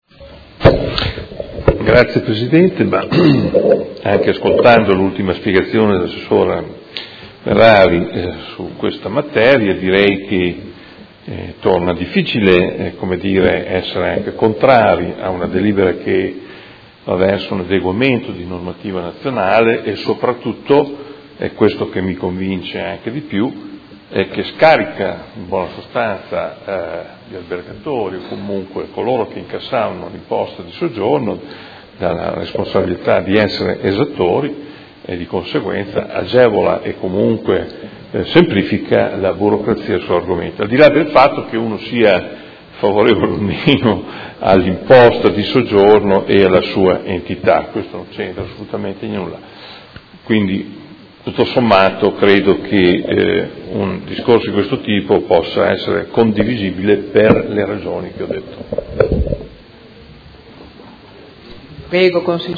Adolfo Morandi — Sito Audio Consiglio Comunale
Seduta del 30/03/2017. Dichiarazione di voto su proposta di deliberazione: Rettifiche e integrazioni ai Regolamenti comunali sul diritto di interpello del contribuente, delle entrate tributarie e dell’imposta di soggiorno